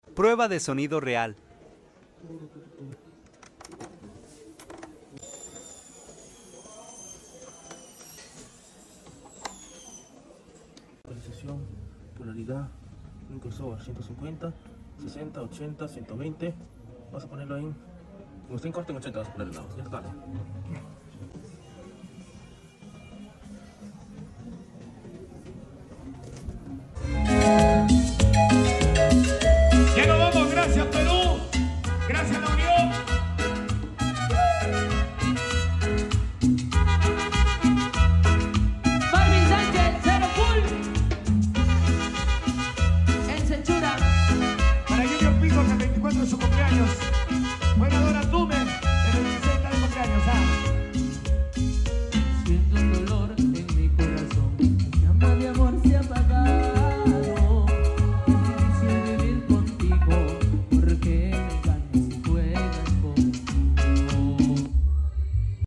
A&AAUDIO PRUEBA DE SONIDO REAL Sound Effects Free Download